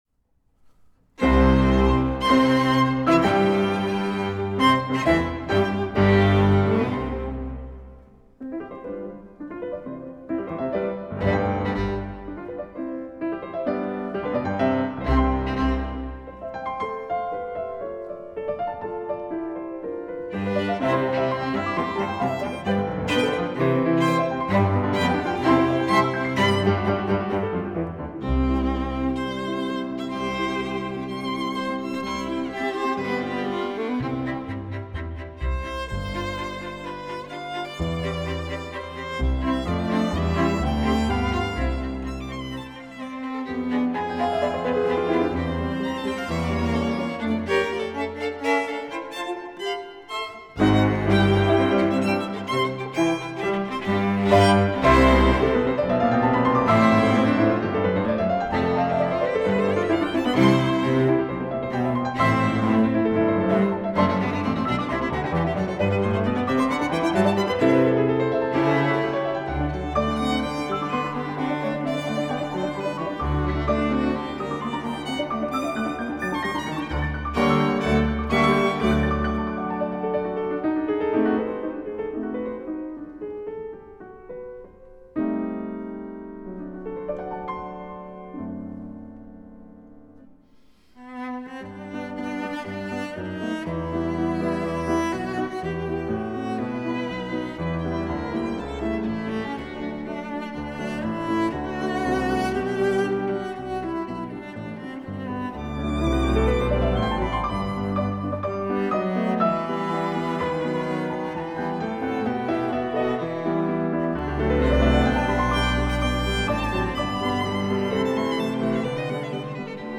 Both, however, used the unusual combination of piano, violin, viola, cello and double bass for their ‘Military’ and ‘Trout’ Quintets.